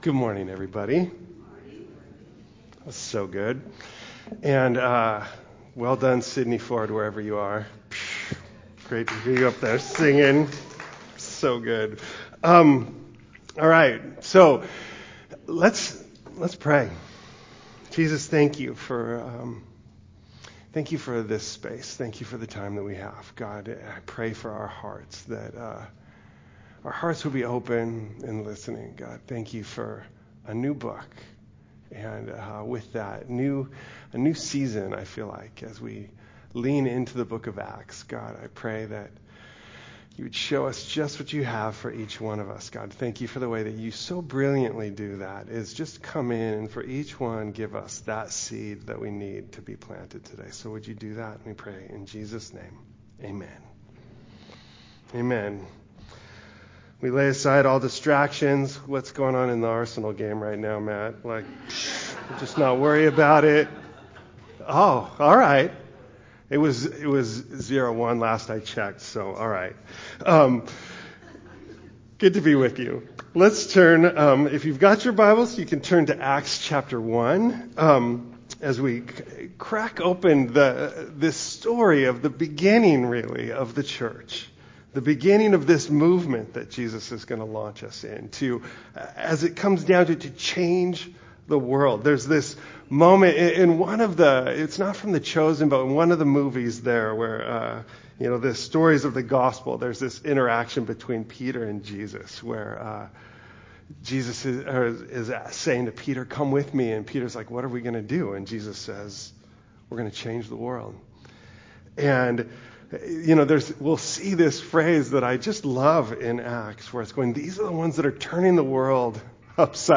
introductory message on the book of Acts